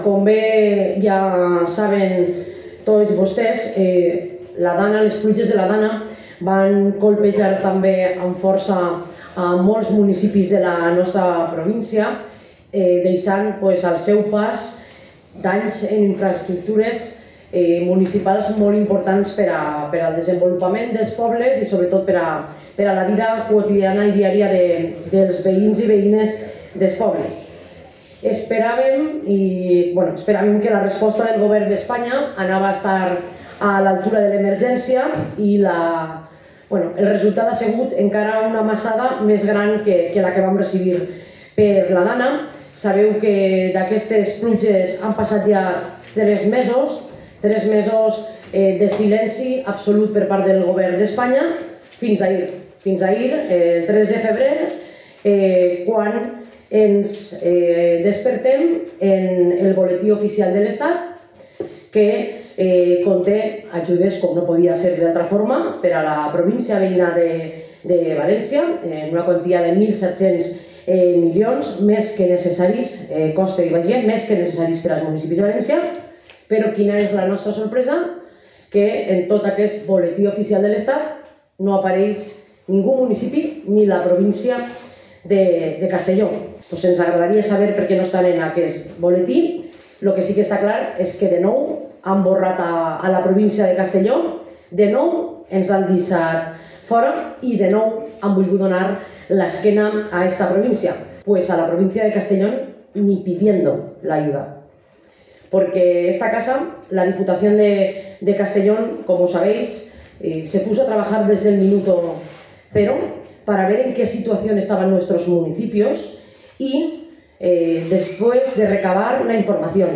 La presidenta de la Diputación Provincial de Castellón ha comparecido este martes ante los medios de comunicación y ha anunciado cuatro acciones para lograr que el Gobierno Central rectifique e incluya a la provincia de Castellón en el listado de beneficiarios de las subvenciones para financiar obras de reparación, restitución o reconstrucción de infraestructuras dañadas por las lluvias torrenciales del pasado noviembre.